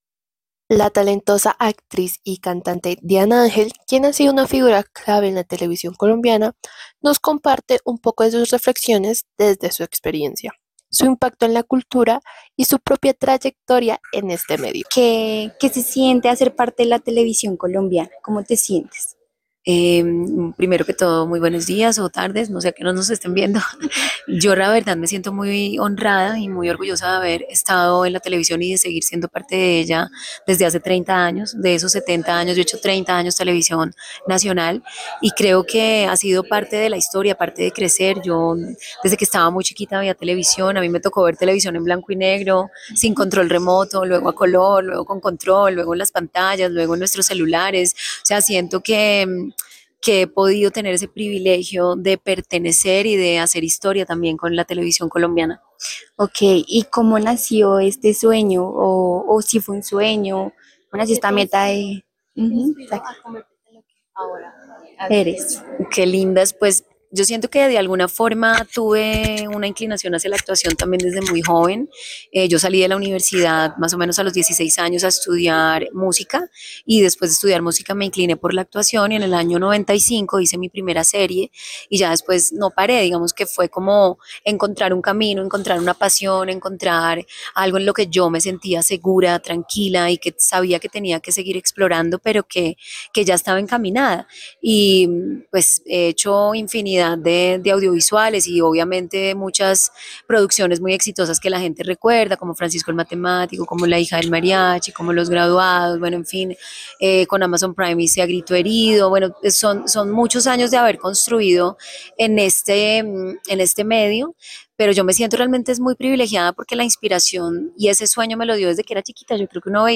001-Entrevista-Diana-Angel-vicepresidenta-de-ACA.mp3